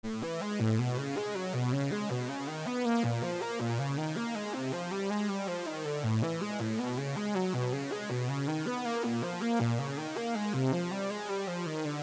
short pangram tunes. the second one is older and most of the notes are bunched toward the end, so it’s not as elegant.